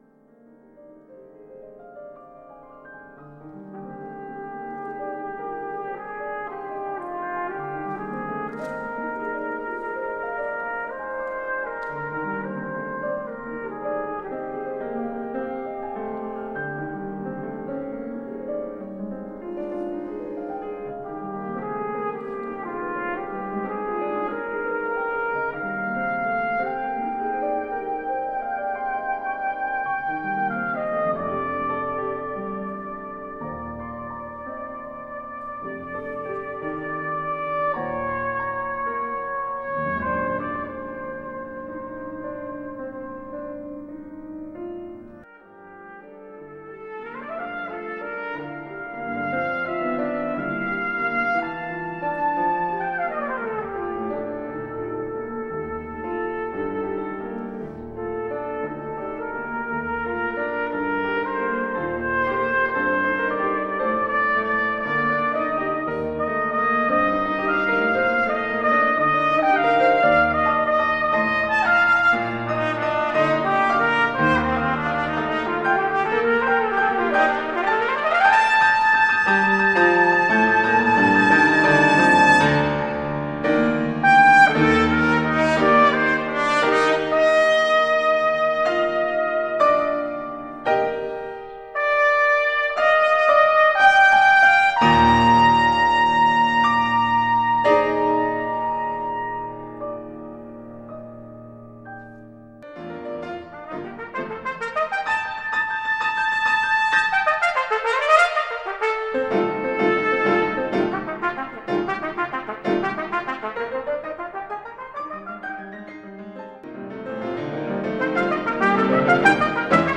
This concerto is written for C trumpet.
The following excerpts are taken from a recital I played at the International Trumpet Conference in 2012.
This particular section is rather long (for a cadenza) and contains musical dialog between the soloist and orchestra (piano).
stephenson-concerto-1st-mvt.mp3